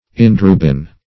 Search Result for " indirubin" : The Collaborative International Dictionary of English v.0.48: Indirubin \In`di*ru"bin\, n. [Indigo + L. ruber red.]